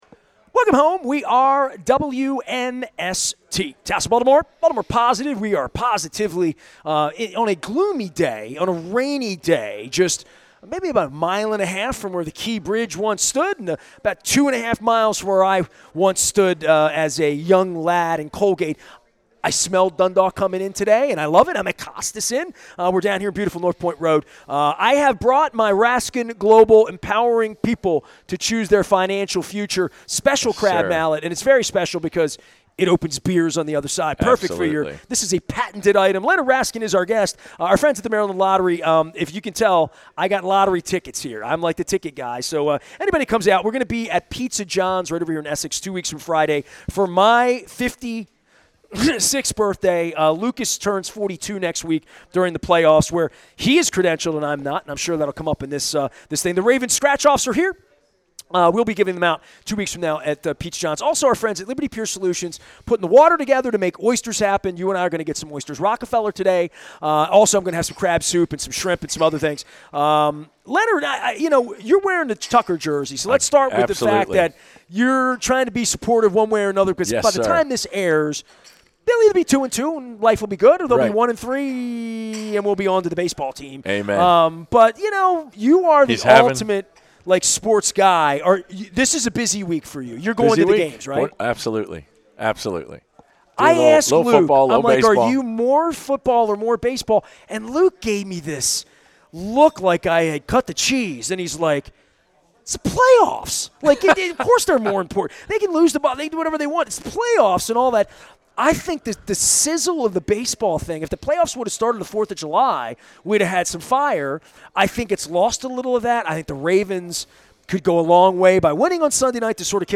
at Costas Inn on the Maryland Crab Cake Tour